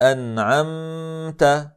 1. Att förlänga ghannas tid utöver vad som krävs när när man gör Iđ-hār, som i: